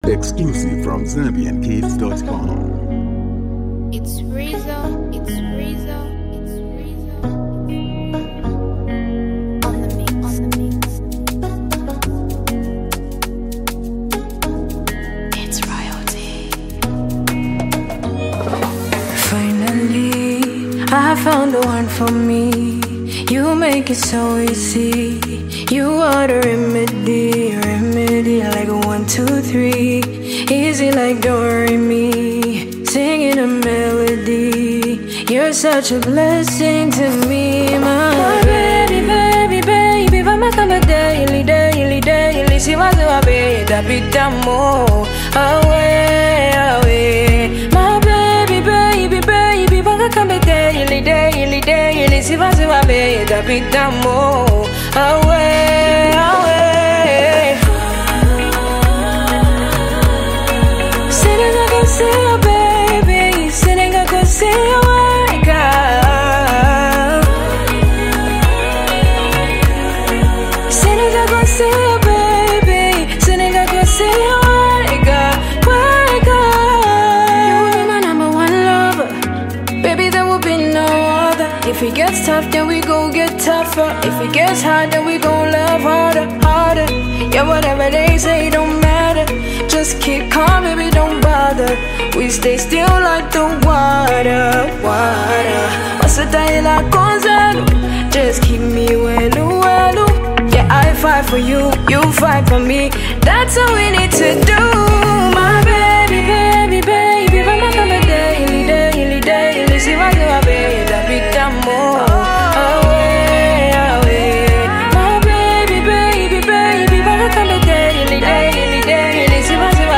Known for blending Afro-pop, R&B, and hip-hop